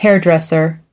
5. hairdresser /ˈherdresər/: thợ cắt tóc, làm tóc